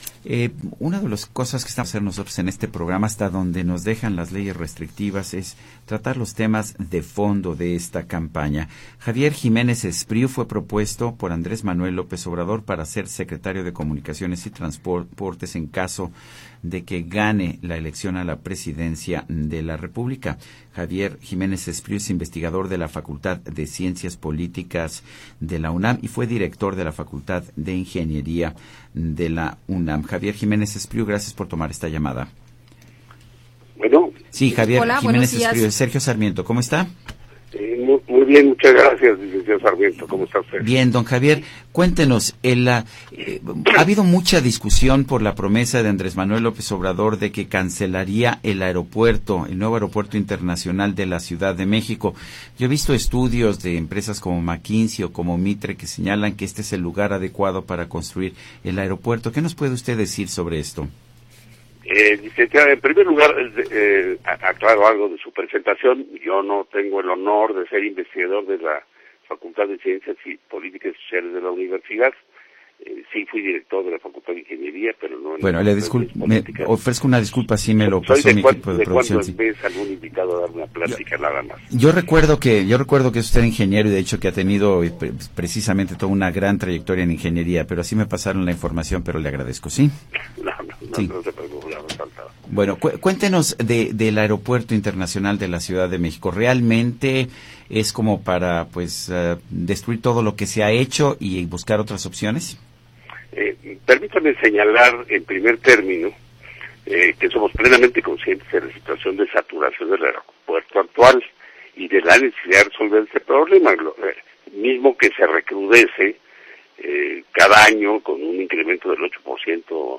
Entrevista en La Red de Radio Red
Entrevista-Sergio-Sarmiento-a-Javier-Jiménez-Espriú.mp3